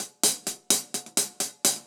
Index of /musicradar/ultimate-hihat-samples/128bpm
UHH_AcoustiHatC_128-02.wav